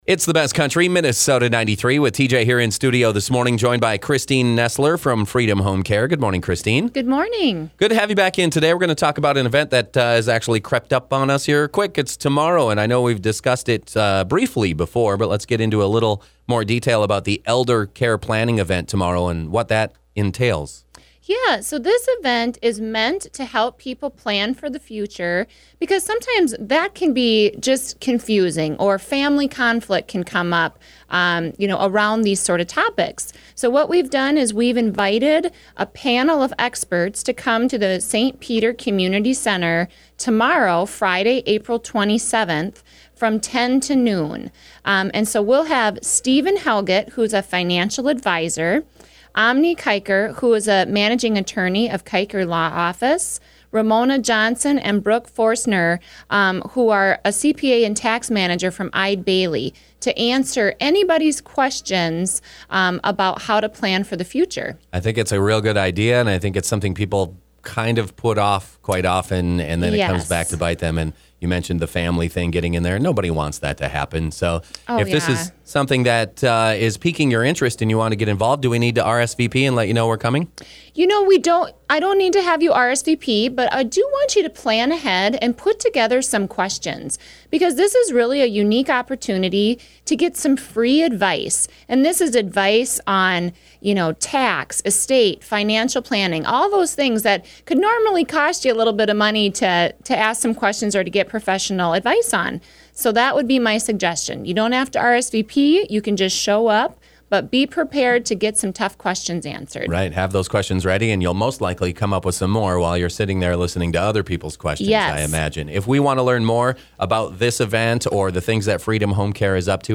Live interviews are aired on Thursday mornings between 9:00 a.m. to 9:20 a.m. on 93.1 FM.